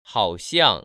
[hǎoxiàng]
하오 시앙